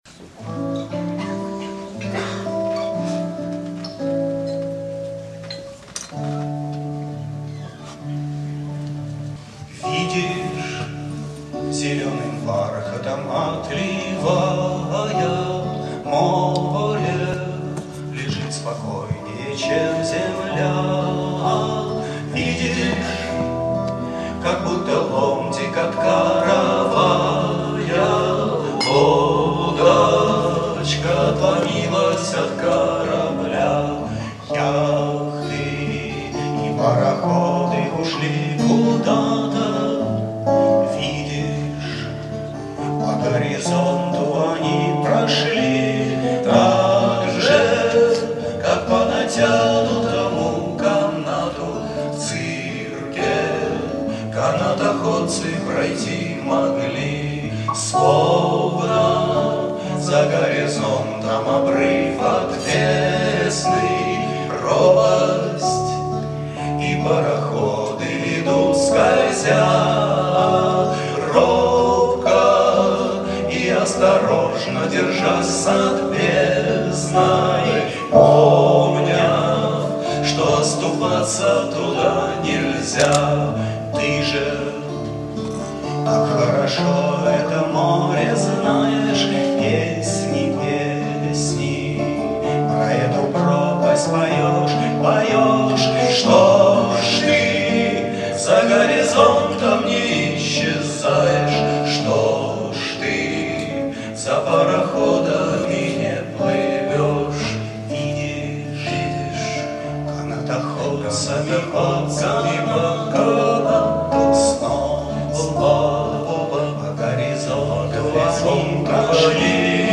По записи с концерта